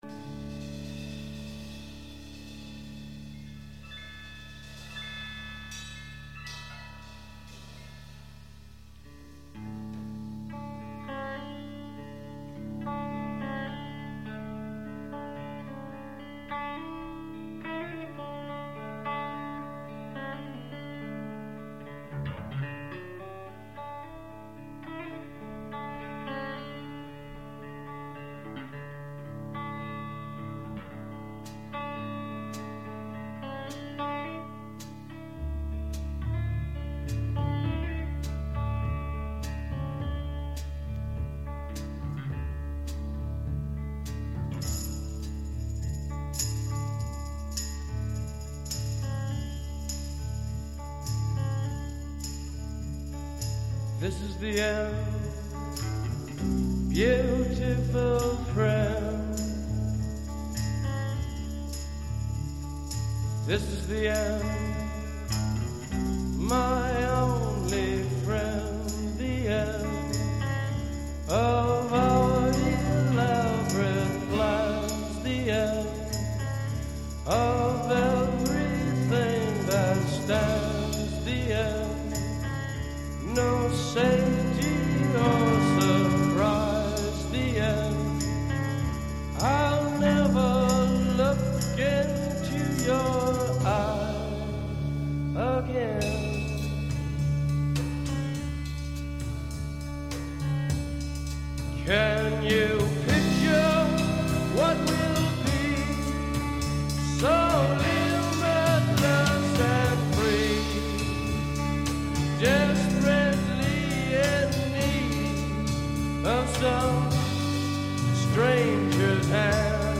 batteria